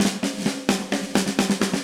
Index of /musicradar/80s-heat-samples/130bpm
AM_MiliSnareC_130-01.wav